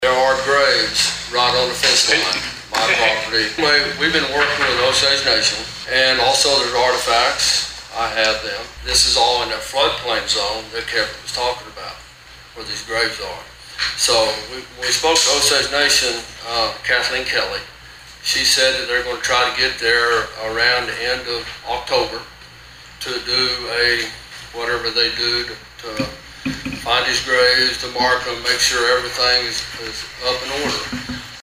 At Monday's Osage County Commissioners meeting, a group of citizens were there to voice their displeasure about the Board considering taking a first step that would allow a housing development to go up on a plat of land near Skiatook.